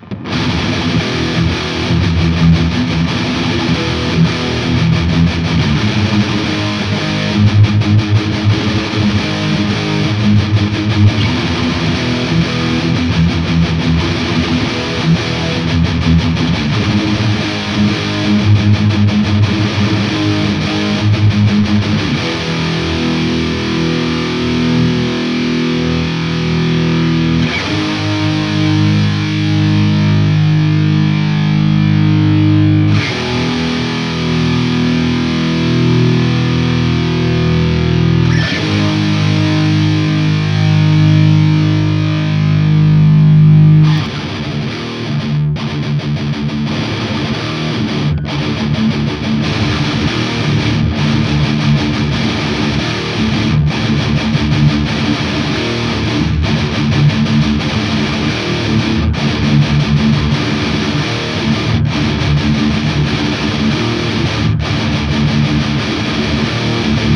J'ai fait 4 prises que j'ai "pané" en 2 par côté.
2 sons de guitares sont mélangés.
Les 2 mélangés :
Ces sons sont bruts, j'ai juste nettoyé le trou au milieu.